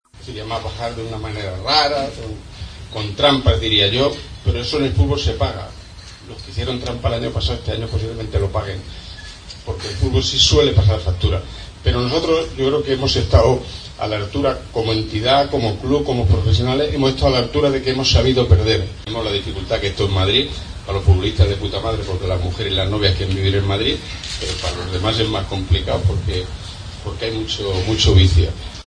El presidente del Getafe habló en la comida con los medios de comunicación: "Bajamos de una manera rara, con trampas, pero eso en el fútbol se paga; los que hicieron trampas el año pasado, este año lo pagarán. Nosotros hemos estado a la altura como entidad y como club, hemos sabido perder. Tenemos la dificultad de que esto es Madrid, para los futbolistas de puta madre porque las mujeres quieren vivir en Madrid, pero los demás es más complicado porque hay mucho vicio"